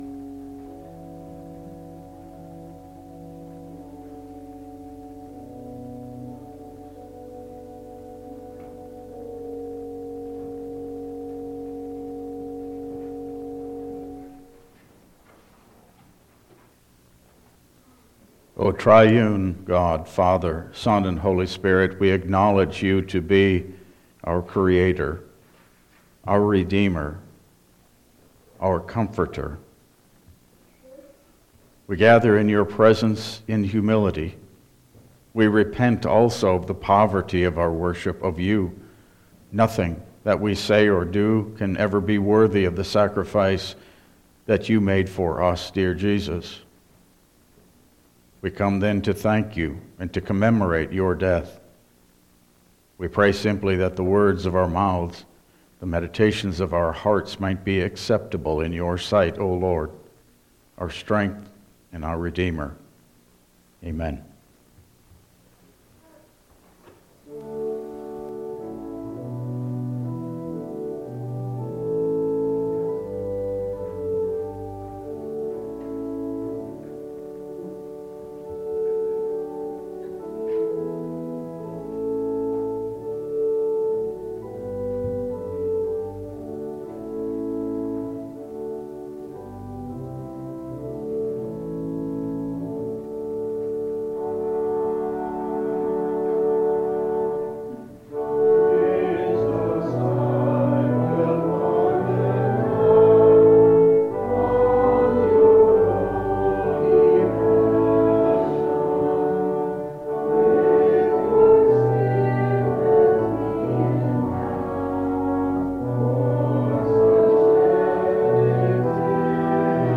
Service Type: Good Friday